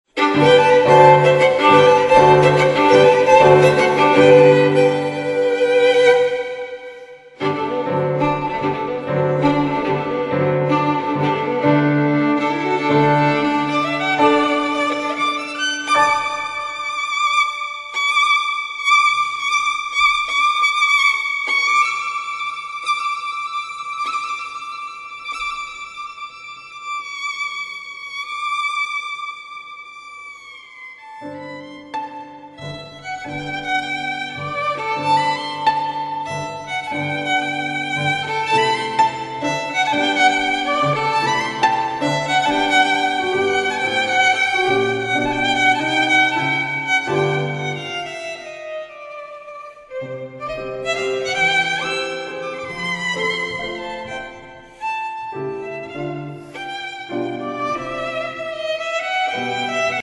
Sorry for some ambiance noises.